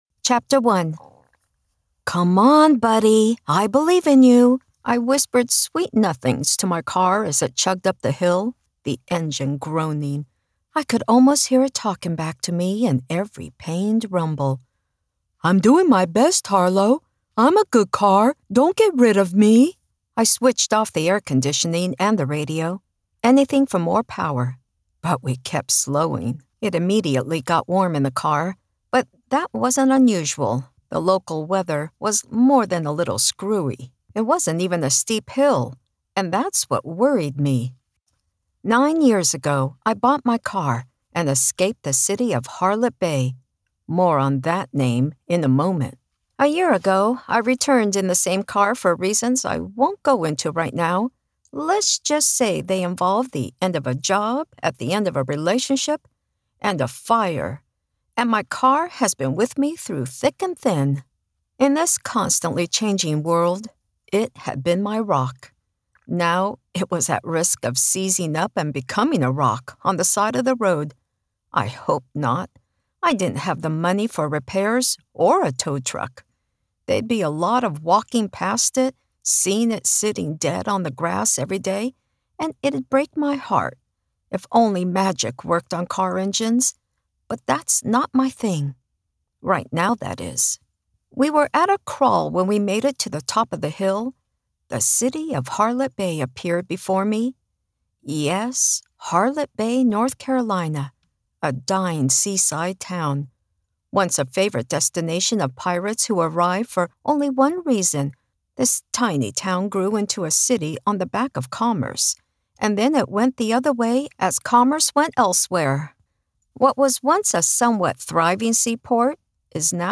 Narration
Middle Aged
My voice is said to be warm, friendly, conversational, casual.